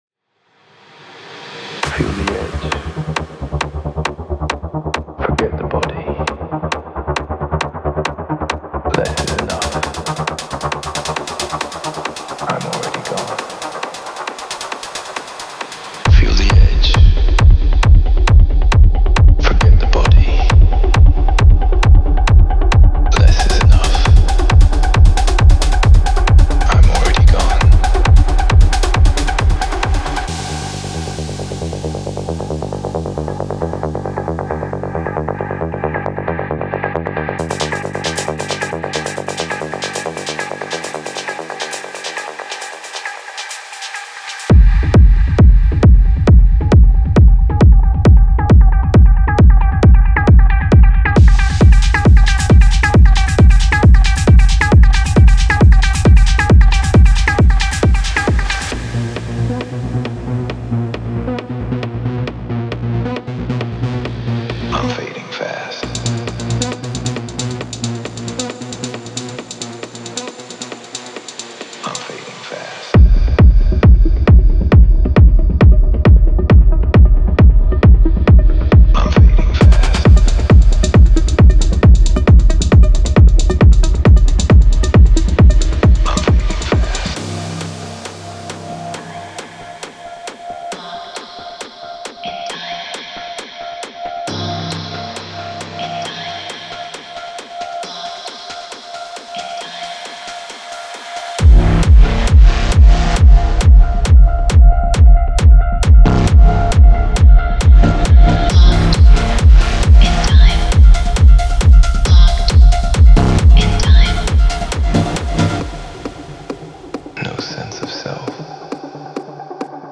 Genre:Techno
ダブを感じさせるキック、滝のように連なるパーカッション、エーテルのようなパッド、そしてサイコアコースティック効果まで。
各要素が調和し、恍惚としたグルーヴを形作ります。
デモサウンドはコチラ↓
135 bpm